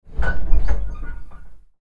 Elevator Stop Thud – Soft Impact Sound Effect
You hear the mechanical sound of a vintage elevator stopping. It slows down with a subtle squeak. A soft thud marks its complete stop.
Elevator-stop-thud-soft-impact-sound-effect.mp3